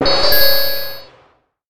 06424 gong glock collect hit
clock collect ding gong hit ring sfx sound sound effect free sound royalty free Sound Effects